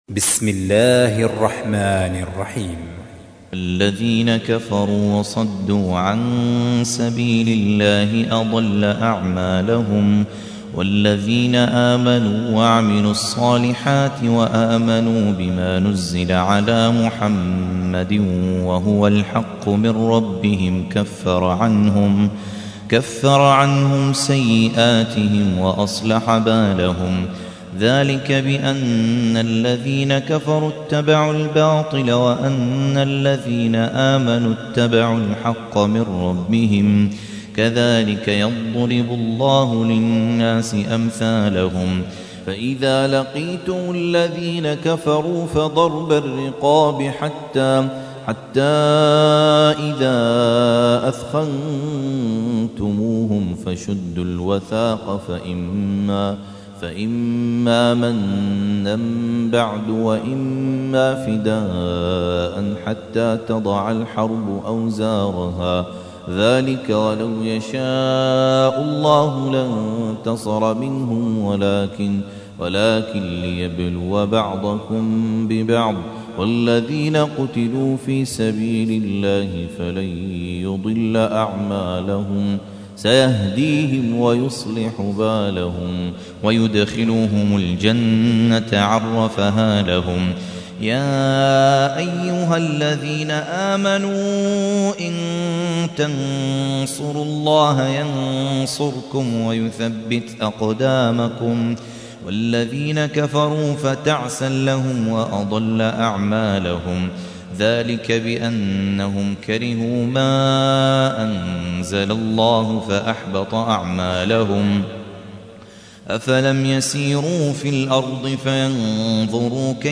47. سورة محمد / القارئ